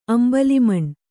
♪ ambalimaṇ